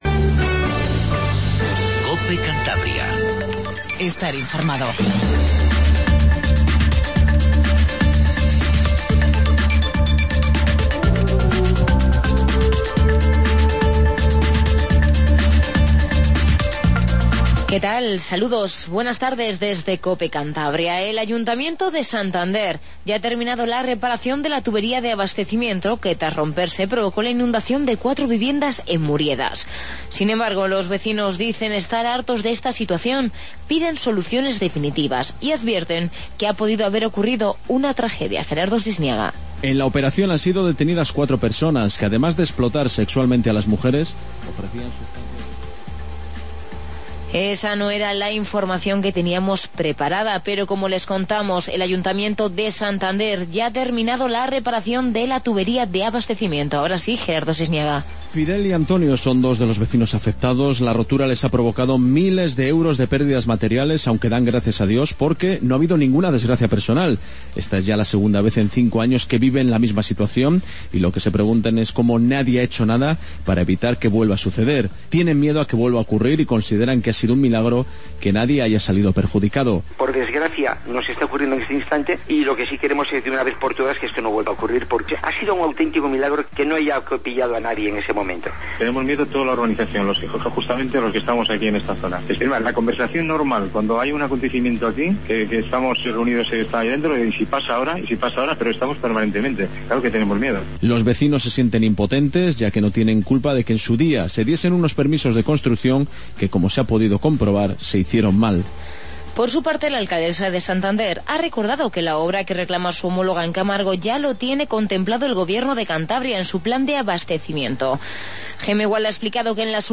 INFORMATIVO REGIONAL 14:50